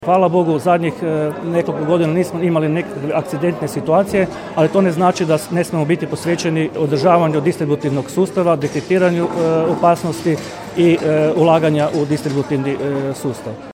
Međimuje plin izborna skupština, Čakovec 17.11.2021.